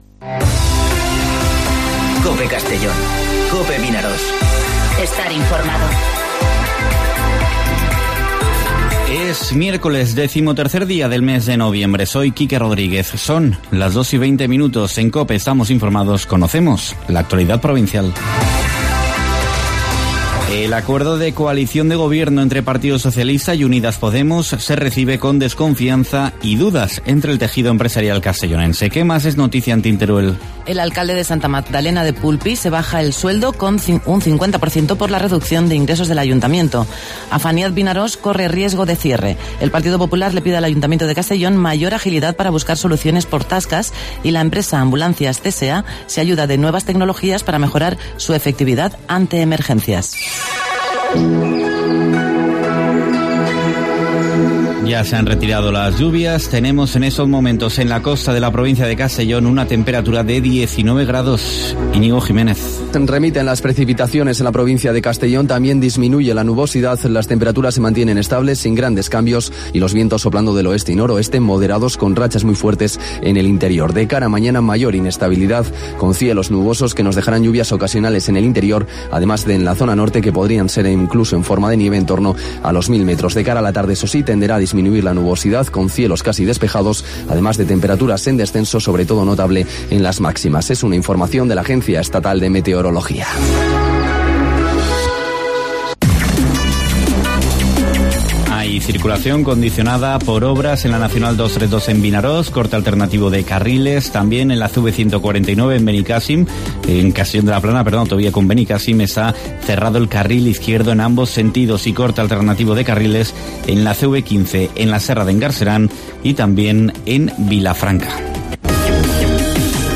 Informativo Mediodía COPE en Castellón (13/11/2018)